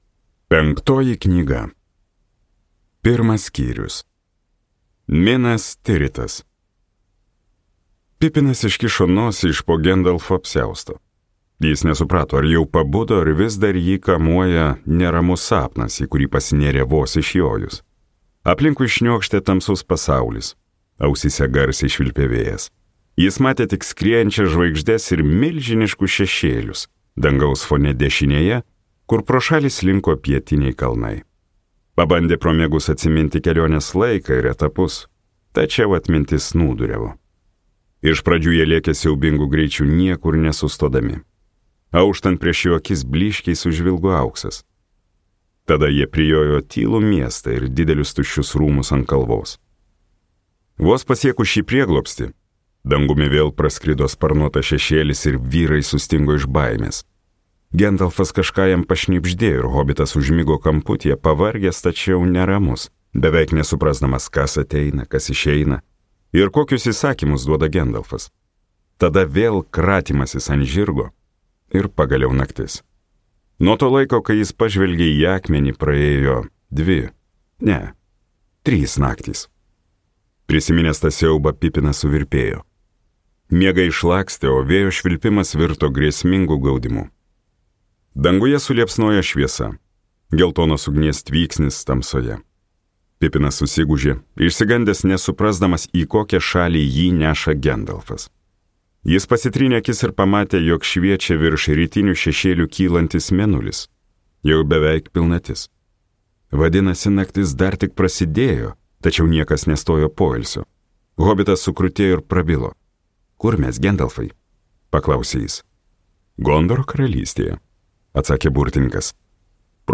III dalis | Audioknygos | baltos lankos